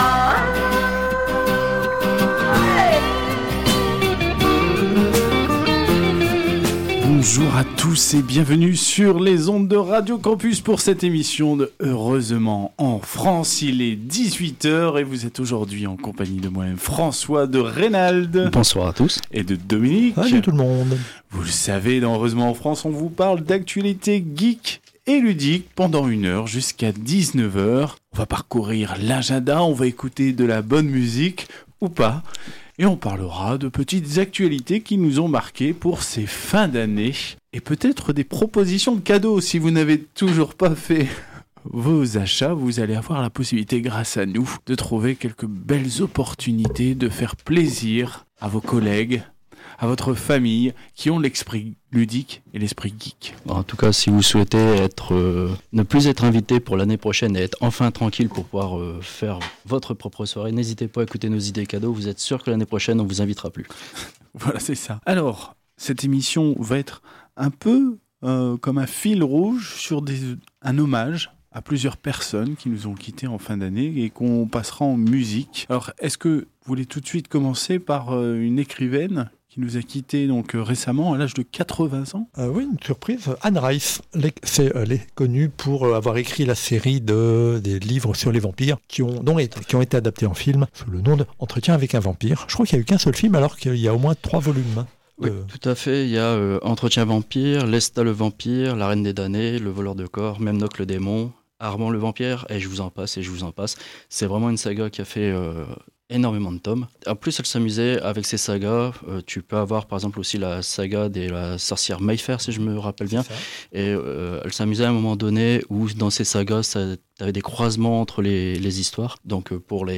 Au sommaire de cet épisode diffusé le 19 décembre 2021 sur Radio Campus 106.6 :